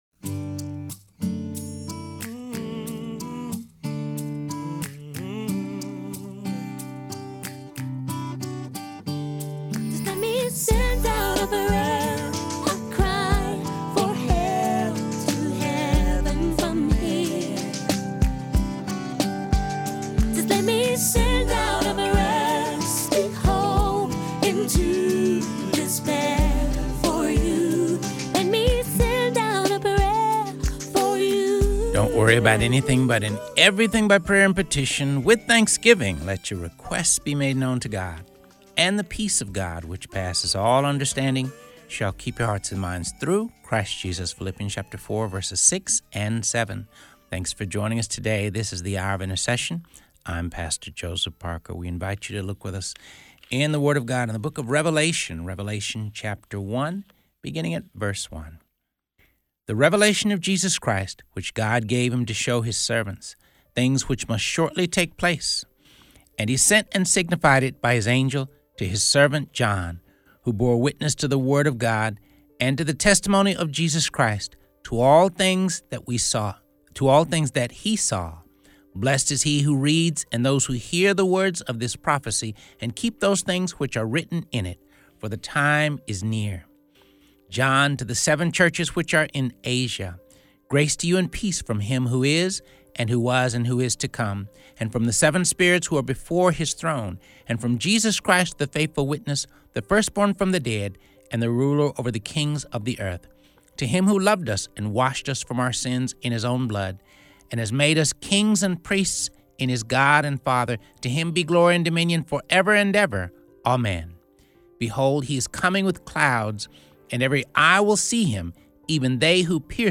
Imagine an episode of Hour of Intercession dedicated to reading passages from the Bible.